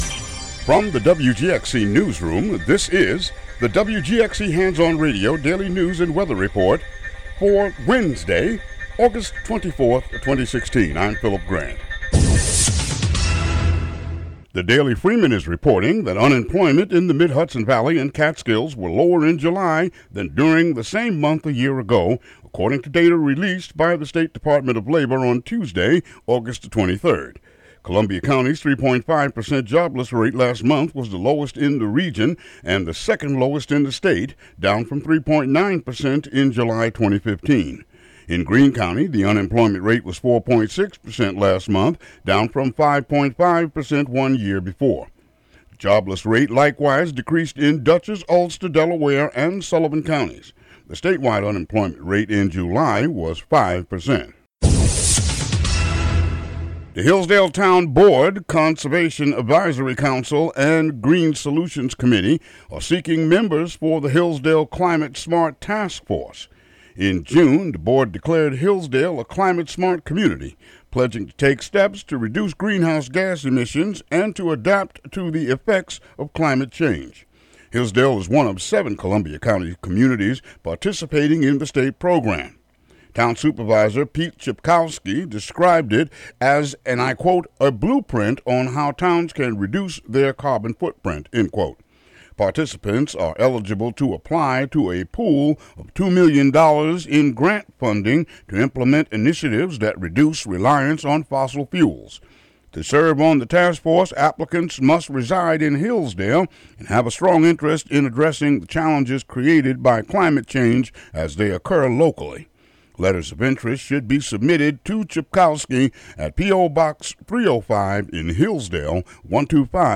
WGXC daily headlines and weather.